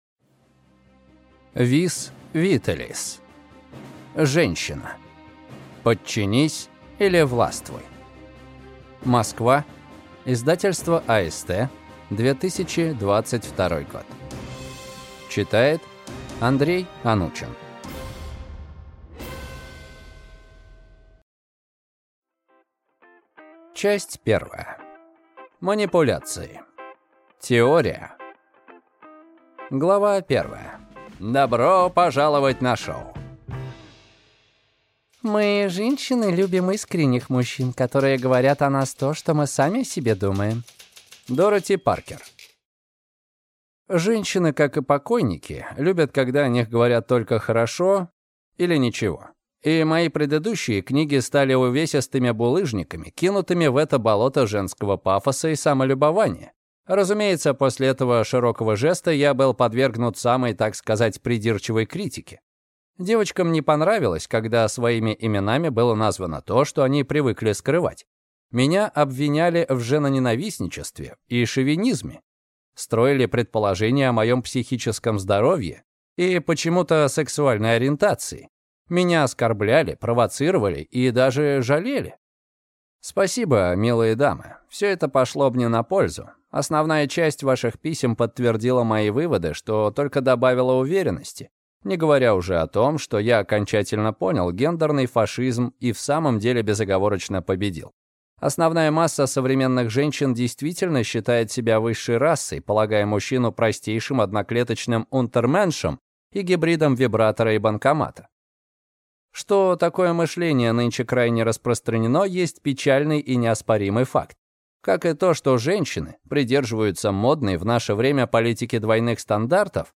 Аудиокнига Женщина. Подчинись или властвуй | Библиотека аудиокниг